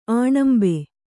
♪ āṇambe